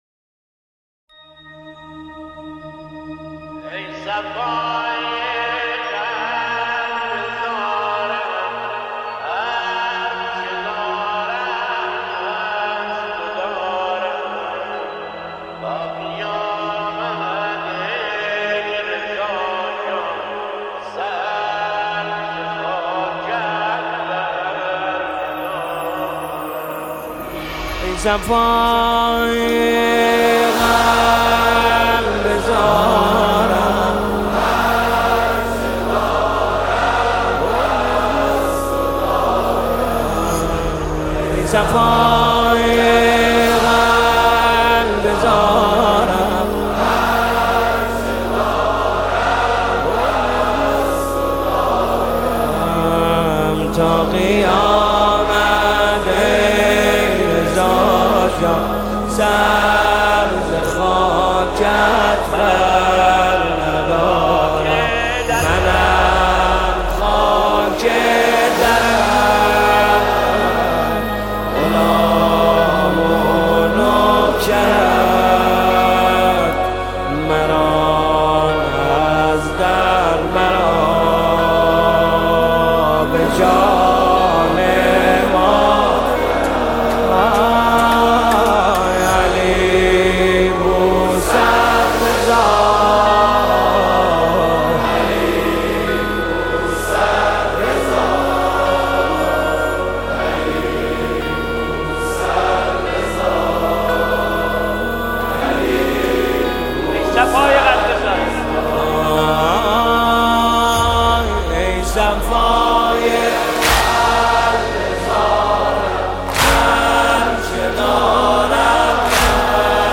زیبا و دلنشین